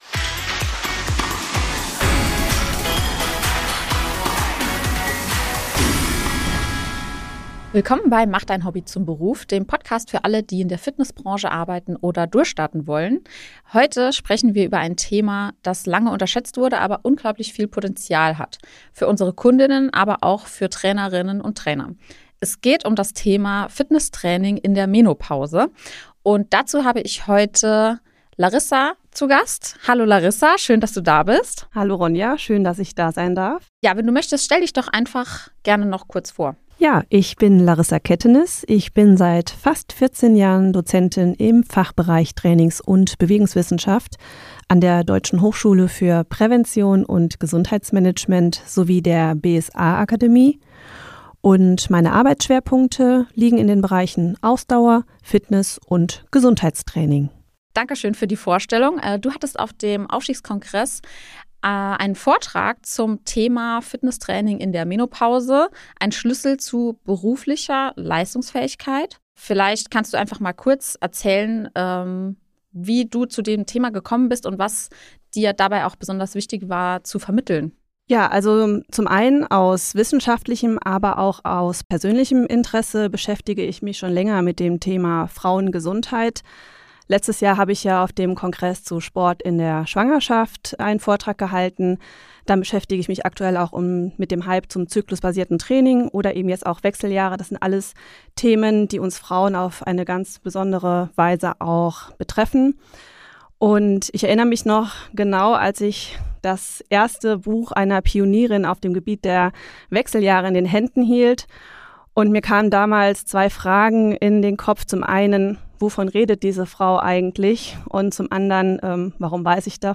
Ein inspirierendes Gespräch für alle, die Frauen auf ihrem Weg zu mehr Gesundheit, Stärke und Leistungsfähigkeit begleiten wollen – evidenzbasiert, praxisnah und mit Herz.